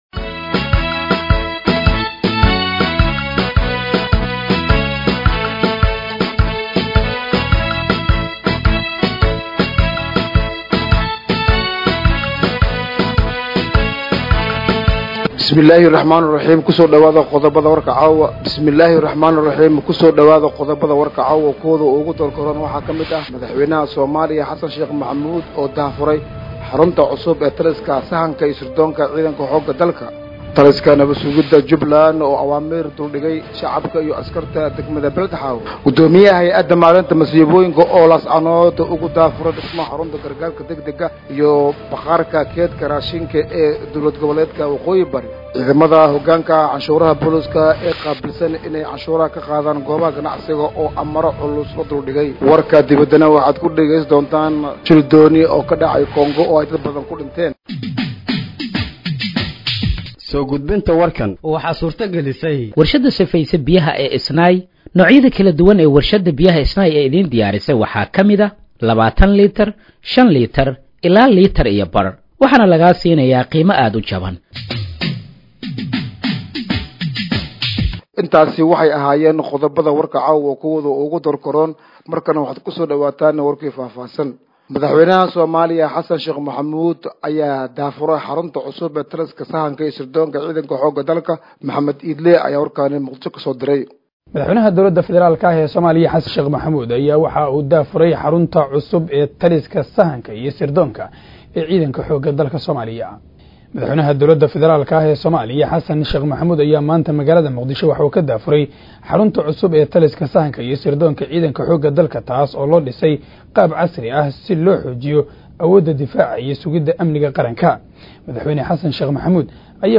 Dhageeyso Warka Habeenimo ee Radiojowhar 13/09/2025